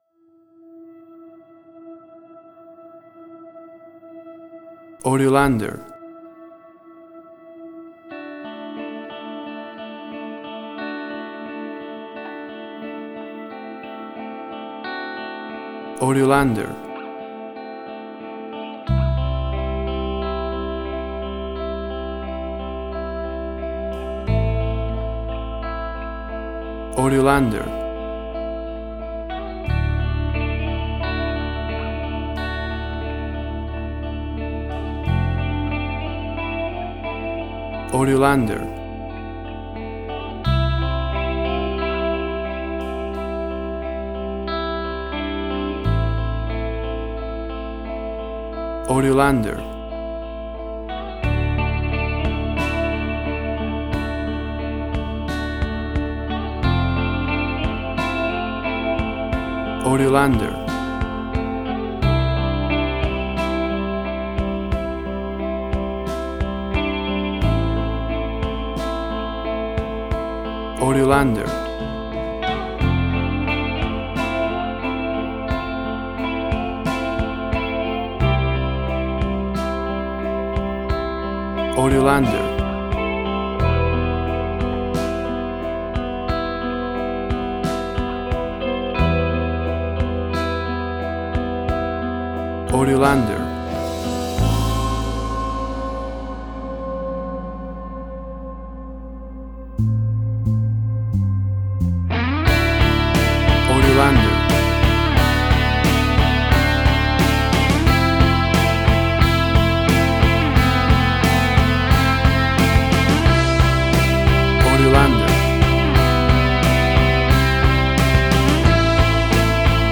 Suspense, Drama, Quirky, Emotional.
Tempo (BPM): 88